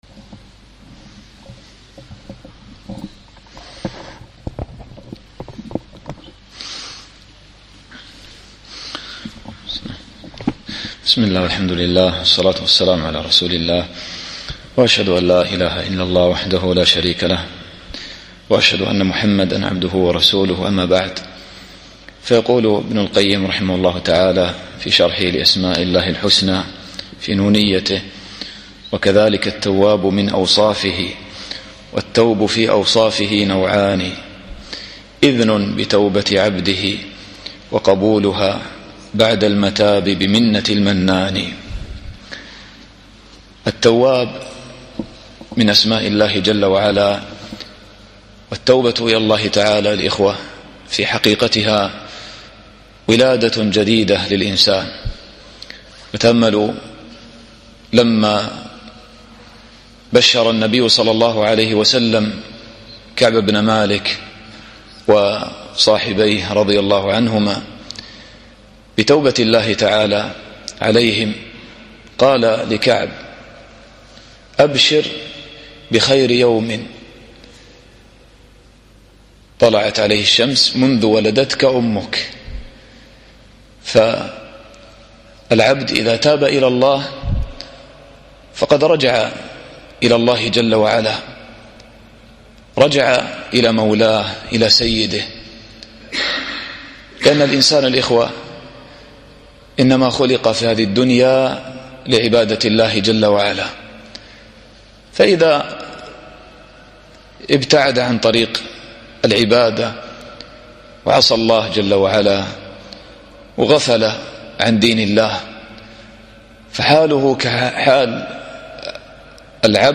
الدرس الثاني والثلاثون